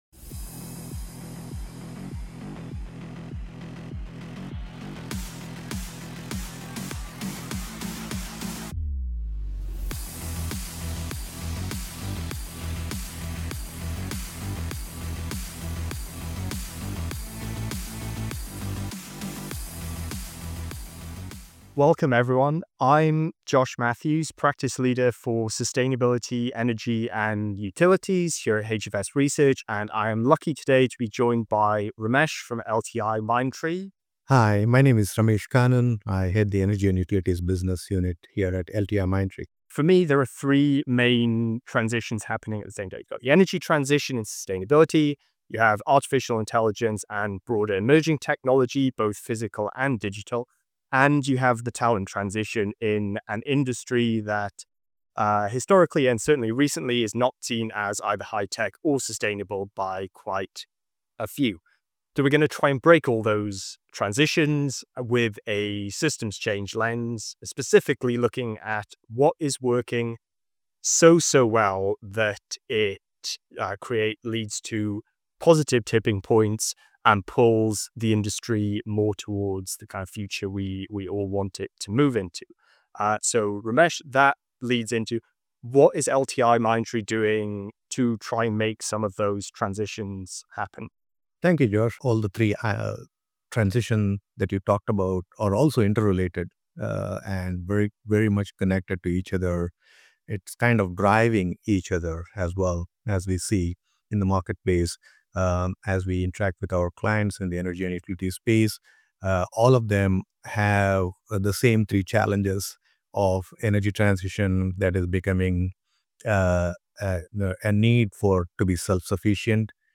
HFS Research and LTIMindtree discuss the intertwined transitions shaping the future of energy and utilities, sustainability, AI, and talent.